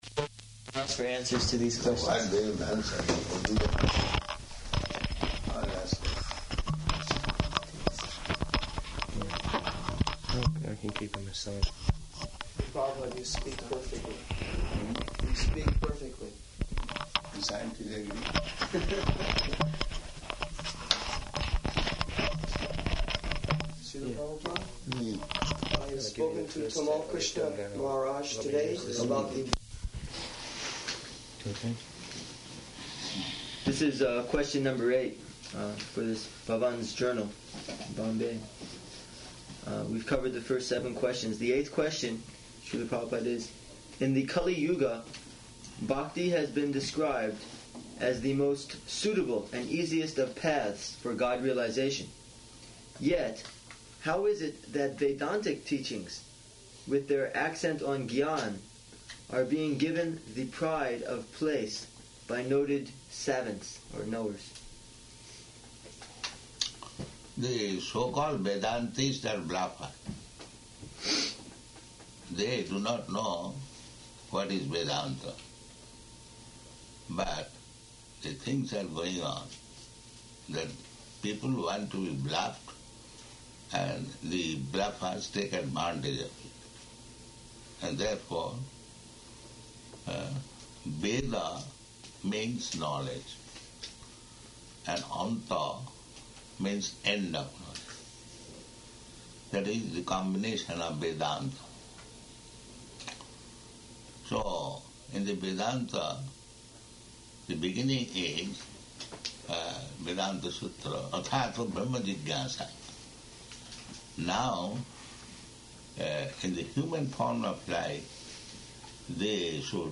-- Type: Lectures and Addresses Dated: June 30th 1976 Location: New Vrindavan Audio file